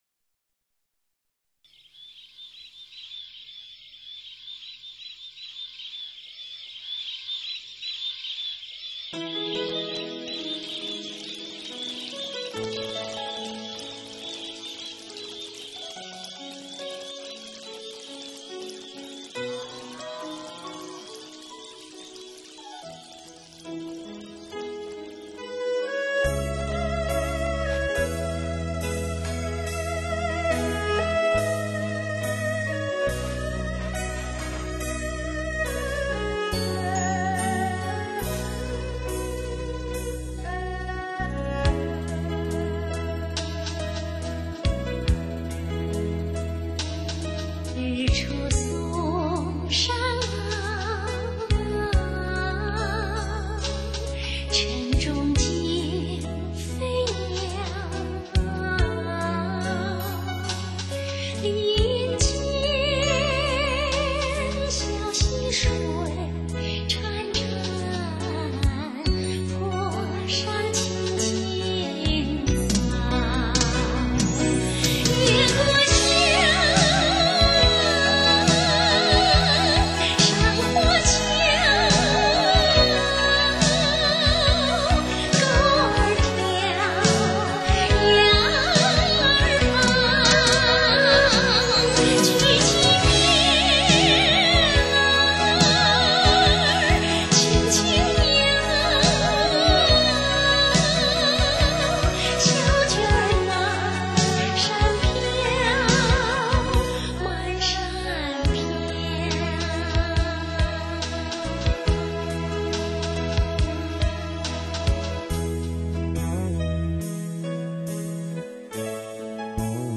淳朴，自然，悠扬的旋律是那八十年代的一 个经典。